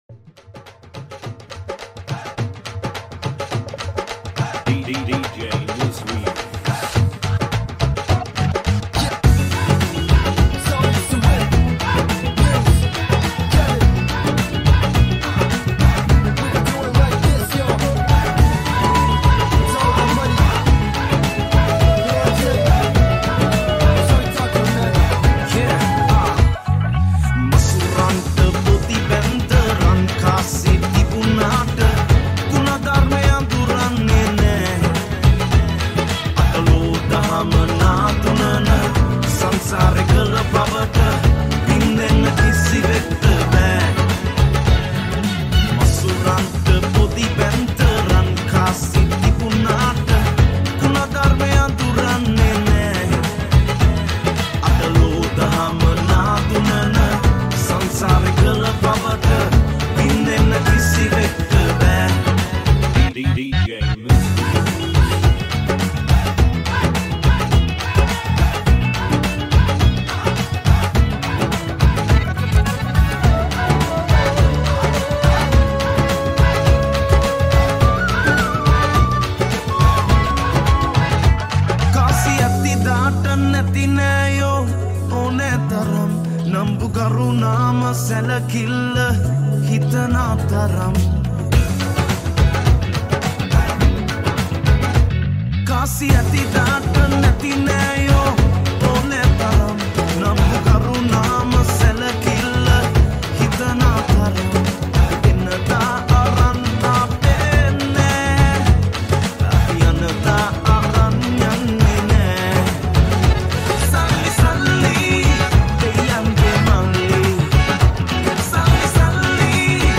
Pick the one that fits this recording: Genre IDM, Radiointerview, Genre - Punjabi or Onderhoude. Genre - Punjabi